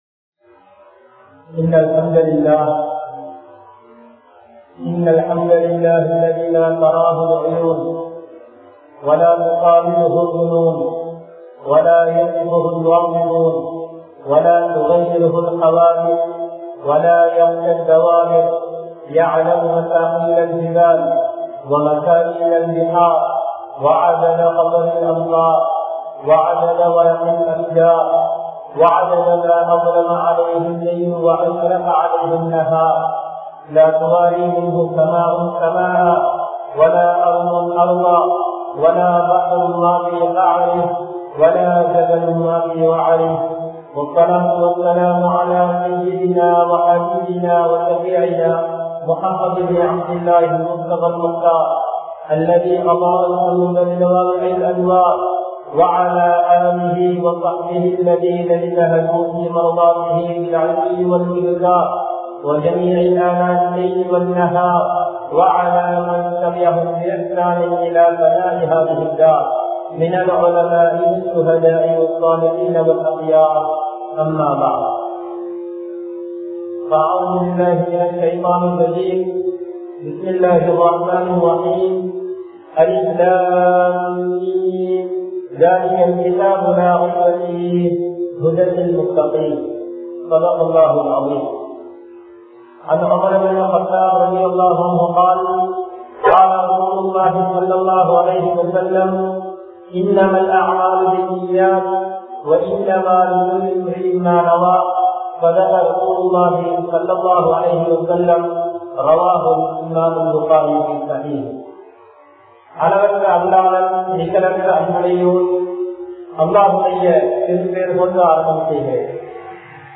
Kodiya Thajjaalin Varukai(கொடிய தஜ்ஜாலின் வருகை) | Audio Bayans | All Ceylon Muslim Youth Community | Addalaichenai